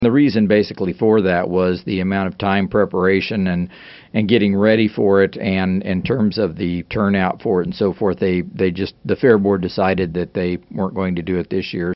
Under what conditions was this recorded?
News Radio KMAN